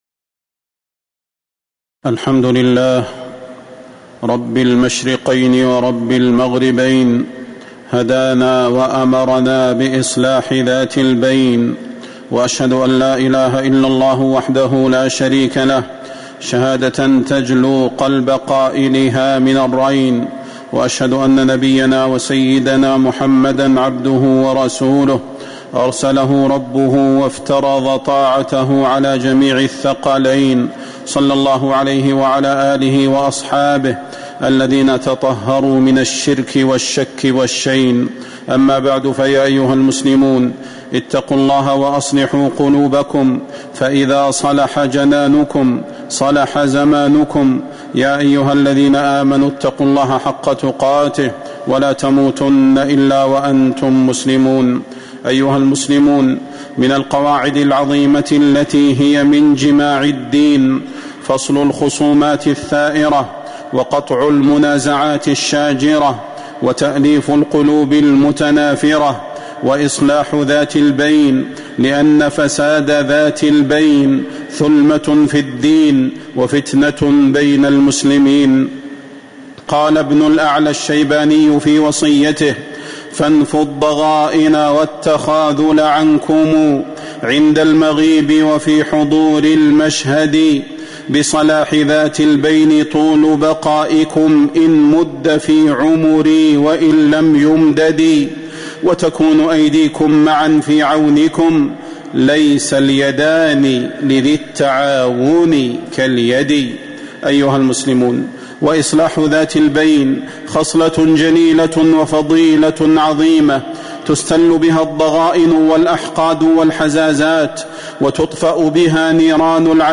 تاريخ النشر ١٩ صفر ١٤٤٦ هـ المكان: المسجد النبوي الشيخ: فضيلة الشيخ د. صلاح بن محمد البدير فضيلة الشيخ د. صلاح بن محمد البدير إصلاح ذات البين